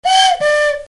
Orologio a cucu
Un cucù da orologio.
Effetto sonoro - Orologio a cucu